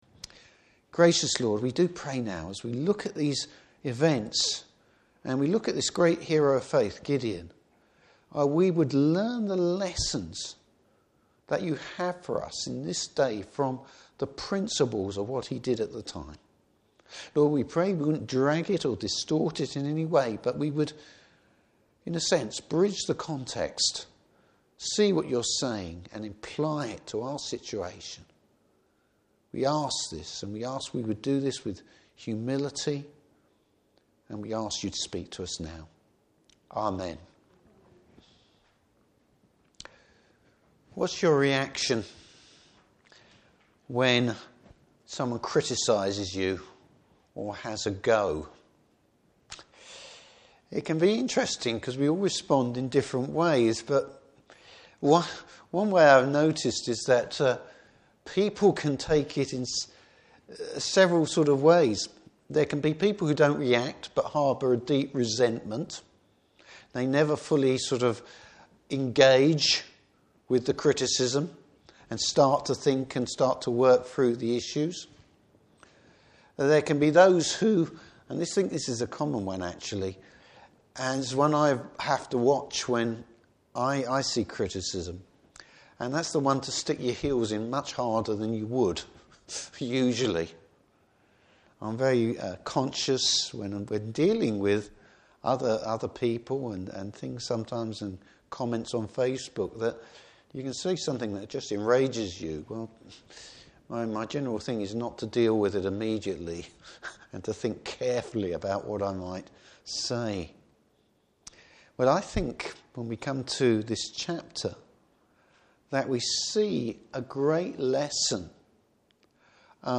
Service Type: Evening Service Bible Text: Judges 8:1-21.